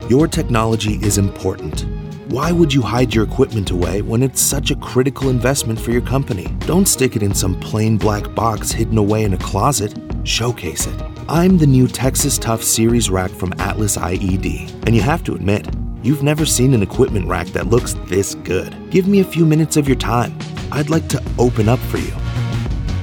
Teenager, Young Adult, Adult
Has Own Studio